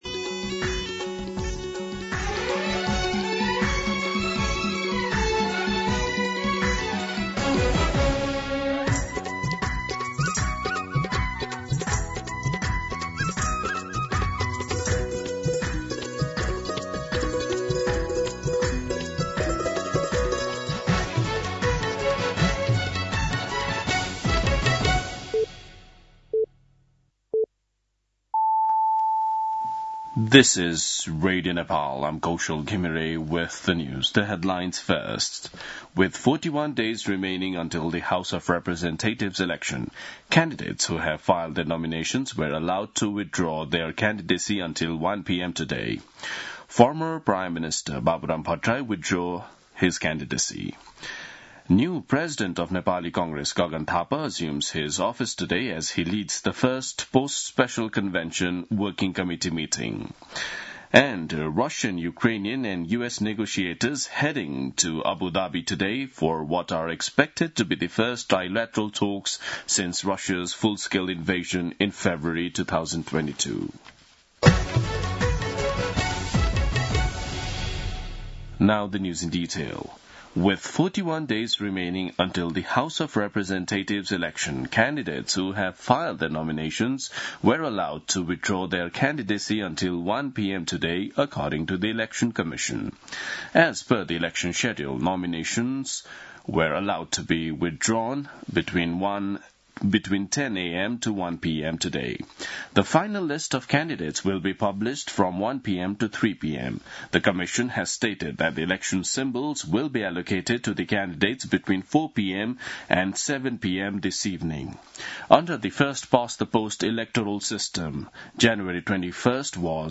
An online outlet of Nepal's national radio broadcaster
दिउँसो २ बजेको अङ्ग्रेजी समाचार : ९ माघ , २०८२